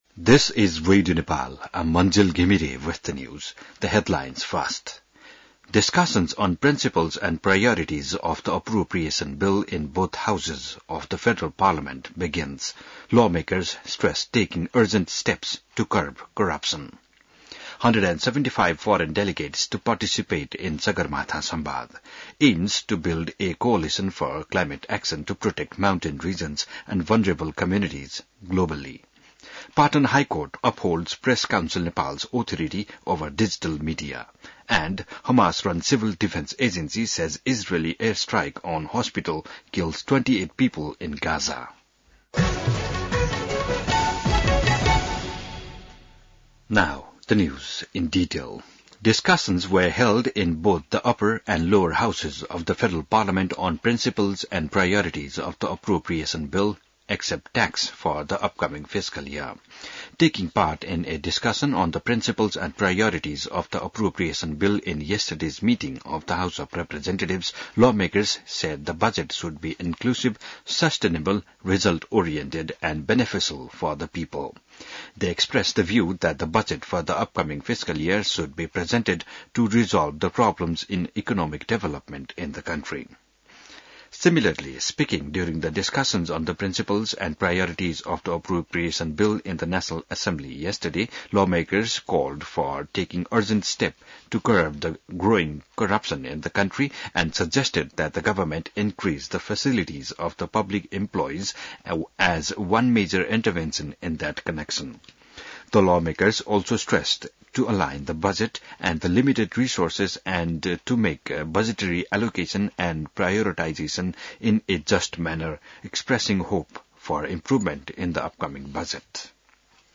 बिहान ८ बजेको अङ्ग्रेजी समाचार : ३१ वैशाख , २०८२